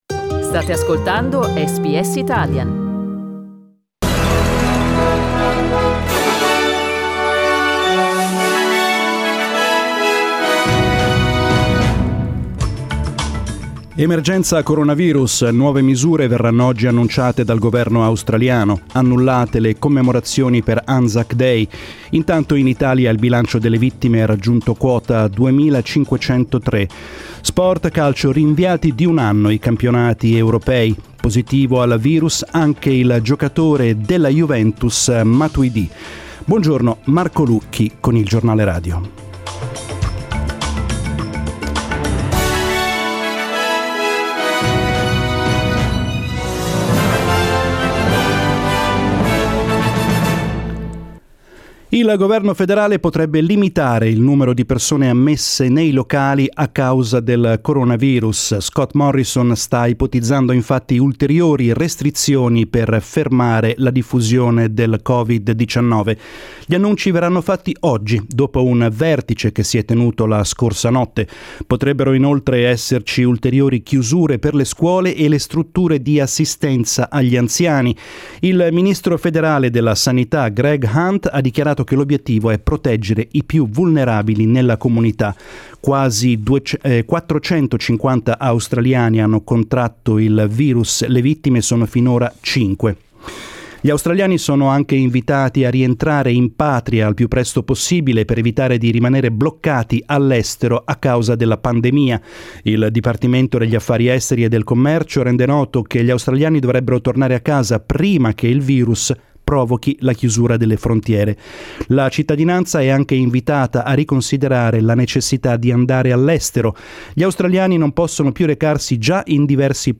Giornale radio mercoledì 18 marzo 2020